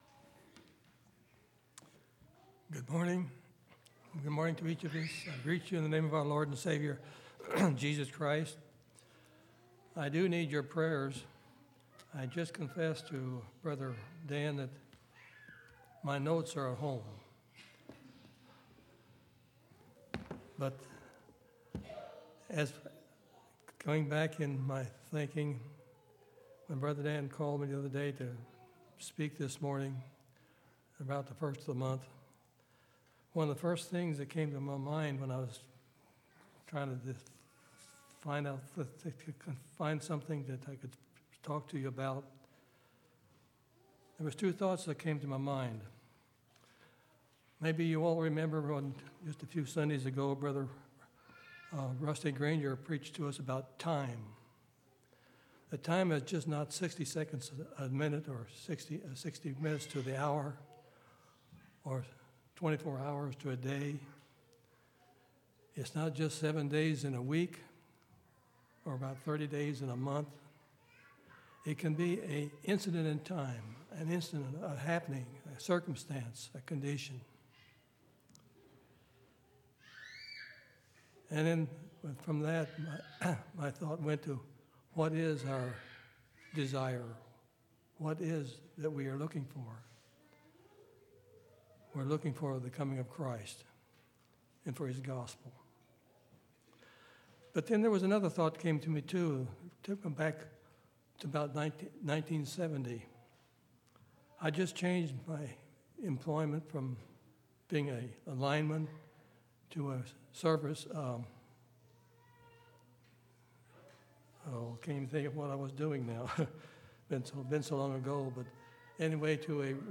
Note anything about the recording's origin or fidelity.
10/20/2019 Location: Temple Lot Local Event